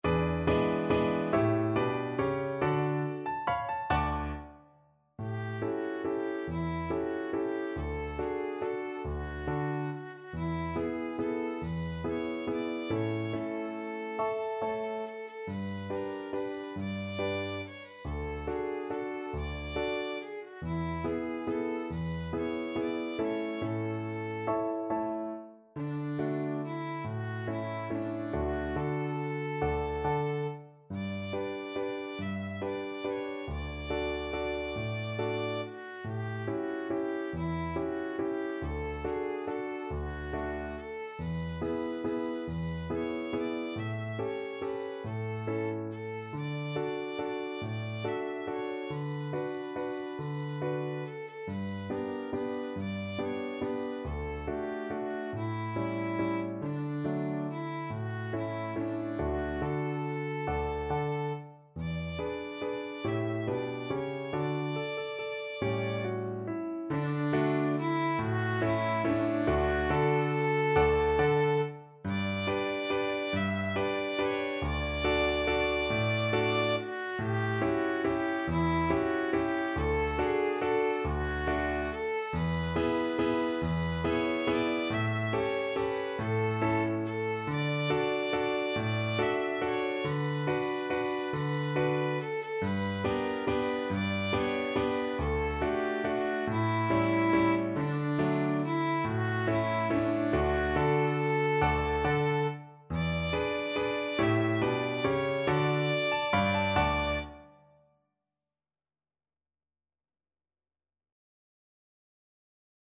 3/4 (View more 3/4 Music)
D5-E6
=140 Moderato Valse